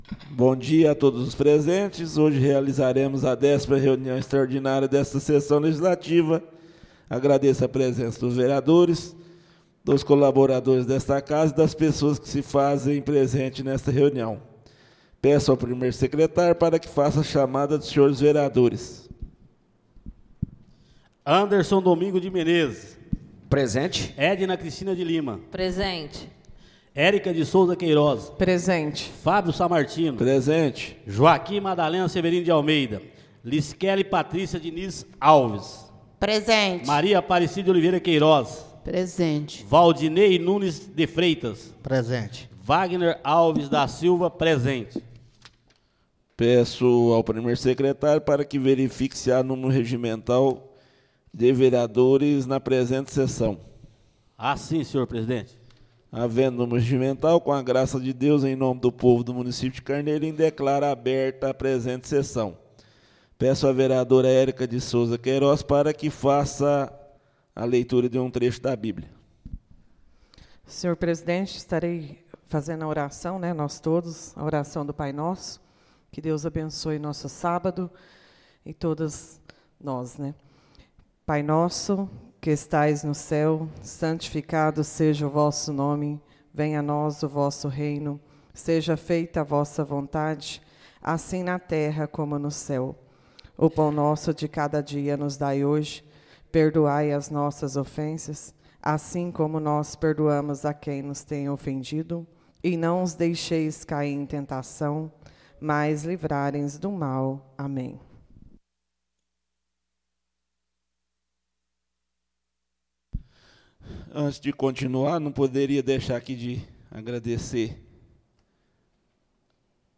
Áudio da 10.ª reunião extraordinária de 2025, realizada no dia 13 de setembro de 2025, na sala de sessões da Câmara Municipal de Carneirinho, Estado de Minas Gerais.